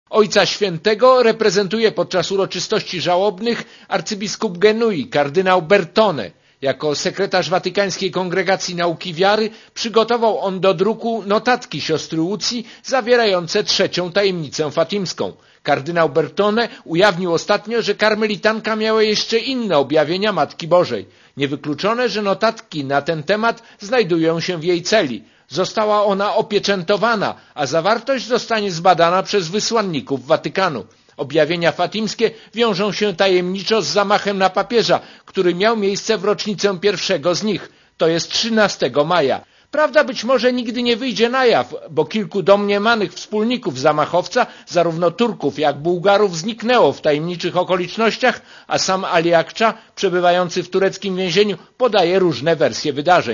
Źródło zdjęć: © RadioZet 15.02.2005 | aktual.: 15.02.2005 13:25 ZAPISZ UDOSTĘPNIJ SKOMENTUJ Korespondencja z Włoch